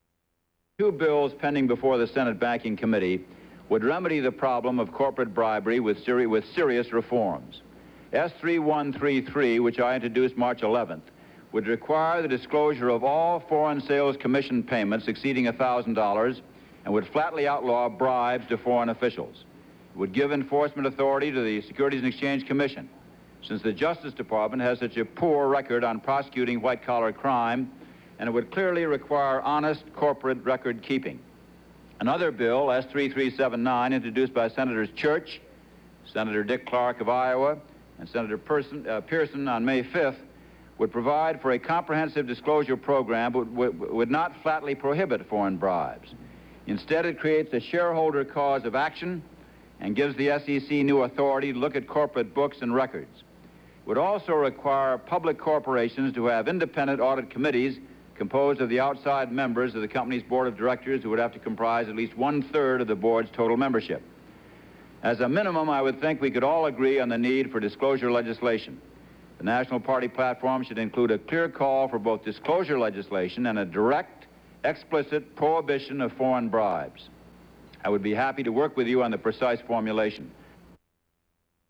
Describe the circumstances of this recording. Subjects Bribery--Law and legislation White collar crimes--Law and legislation United States Material Type Sound recordings Language English Extent 00:01:22 Venue Note Broadcast 1976 May 21.